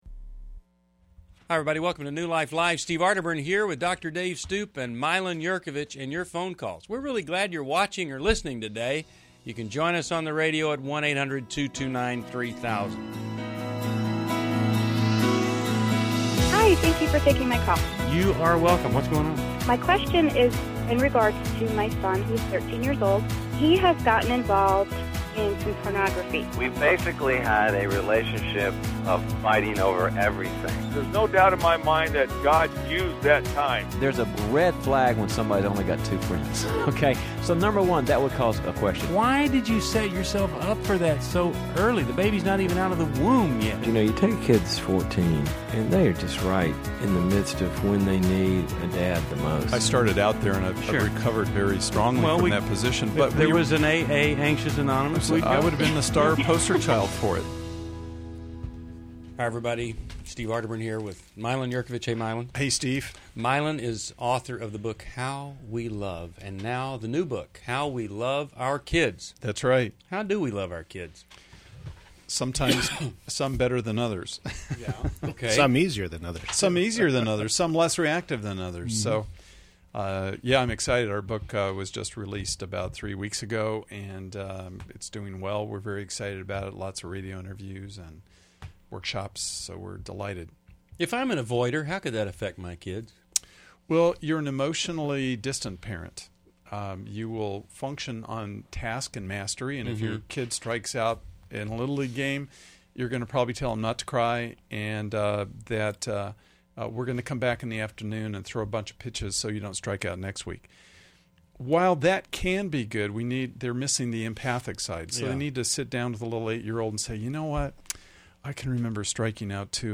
Explore themes of substance abuse, grief, and setting boundaries in relationships on New Life Live: April 5, 2011. Join hosts for compassionate insights and guidance.